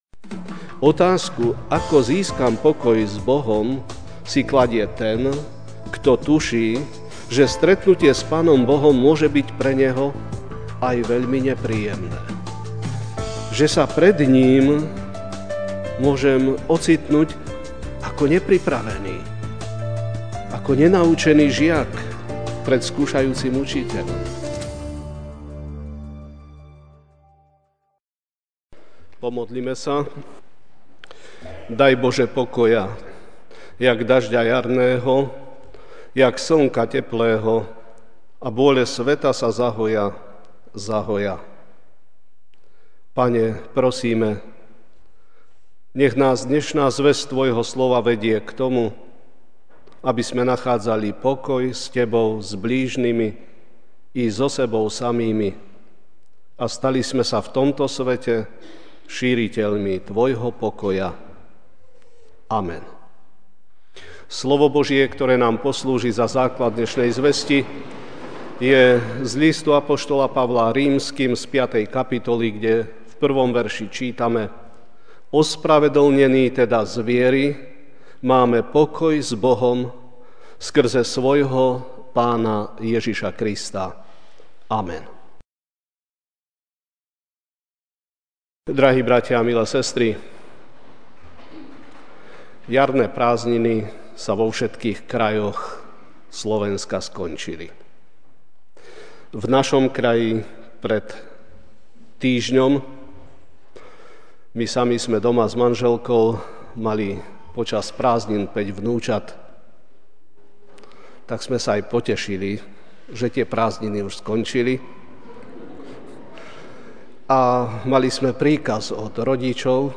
MP3 SUBSCRIBE on iTunes(Podcast) Notes Sermons in this Series Ranná kázeň: Ako nájdem pokoj s Bohom?